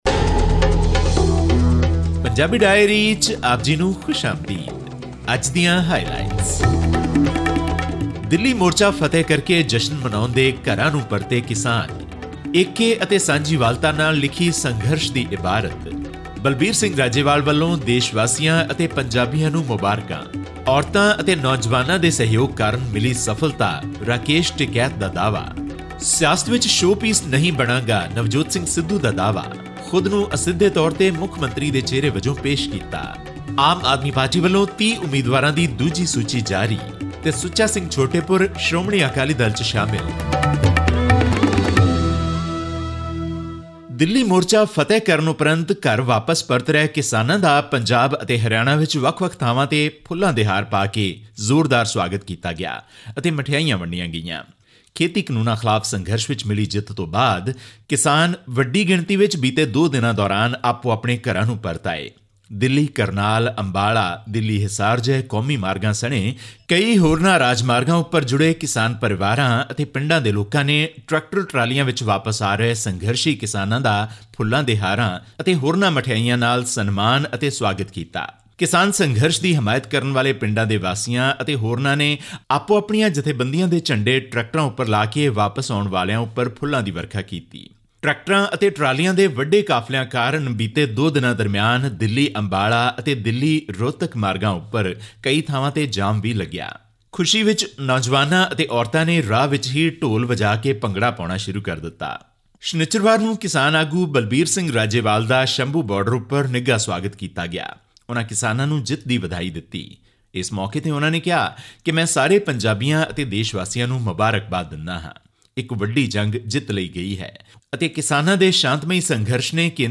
Farmers received a hero's welcome as they uprooted their makeshift homes and returned to their villages after a year-long agitation against the government's three farm laws at the Indian capital's borders. This and much more in our weekly news update from Punjab.